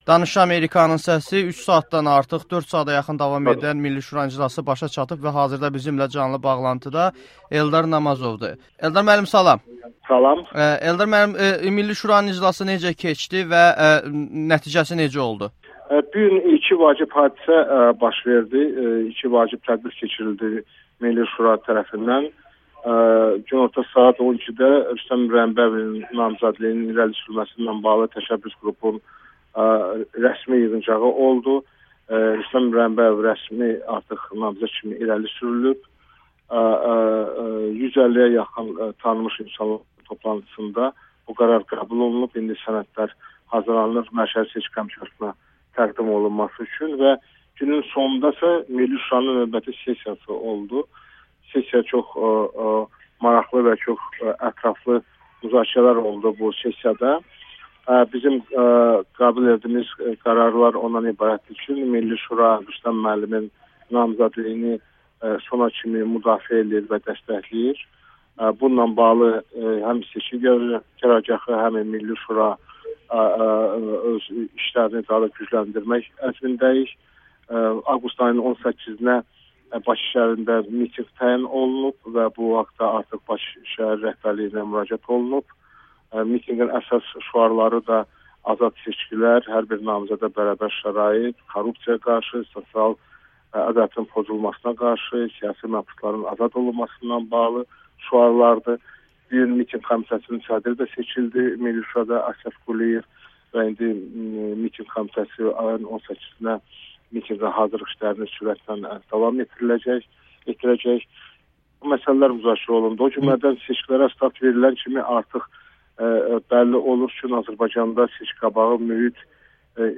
Milli Şura Rüstəm İbrahimbəyovun vahid namizədliyini qüvvədə saxladı [Eldar Namazovla müsahibə]